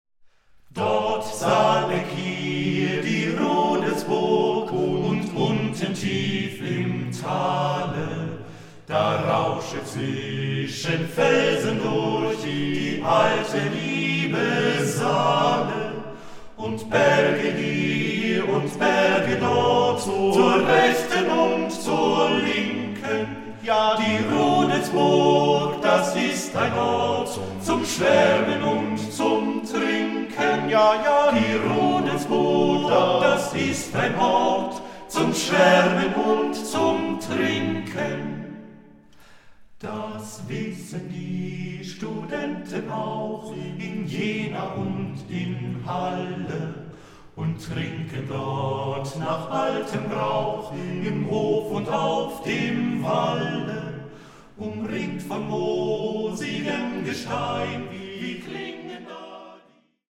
the eight singers revive an entire folk culture!